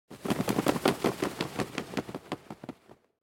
دانلود صدای پرنده 18 از ساعد نیوز با لینک مستقیم و کیفیت بالا
جلوه های صوتی
برچسب: دانلود آهنگ های افکت صوتی انسان و موجودات زنده دانلود آلبوم صدای پرندگان از افکت صوتی انسان و موجودات زنده